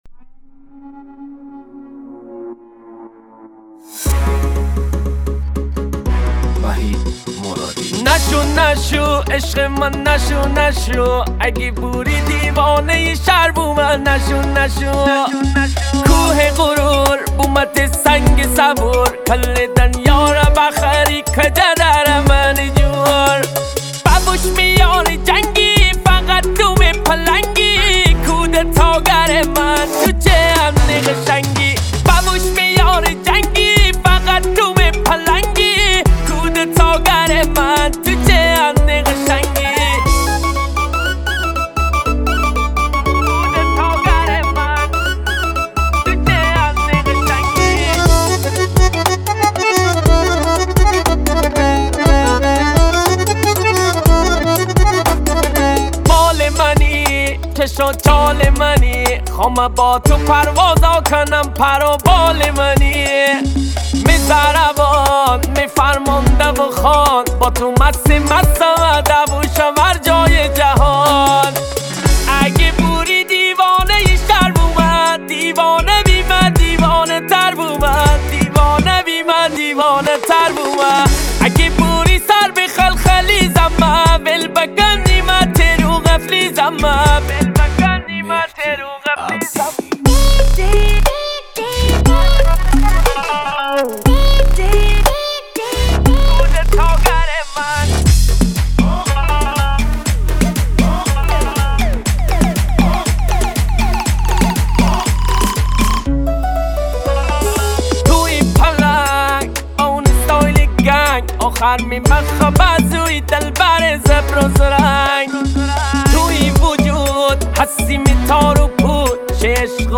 موزیک مازندرانی